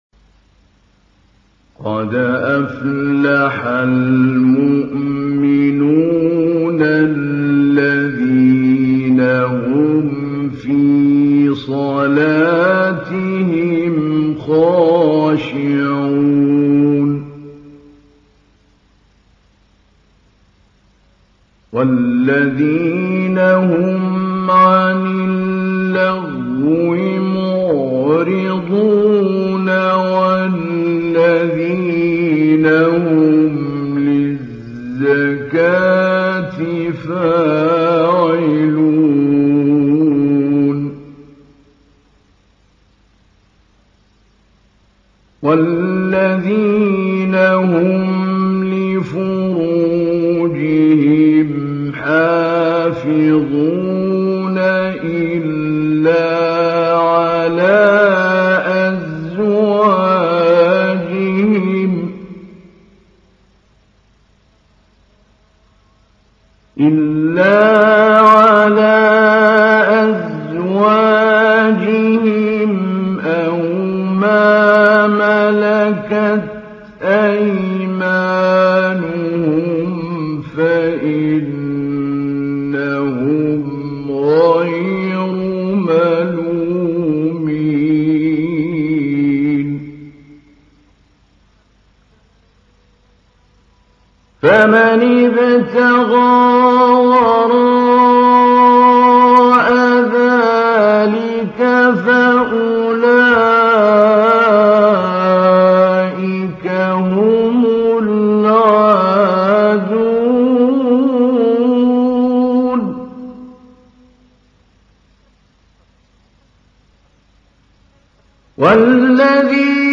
تحميل : 23. سورة المؤمنون / القارئ محمود علي البنا / القرآن الكريم / موقع يا حسين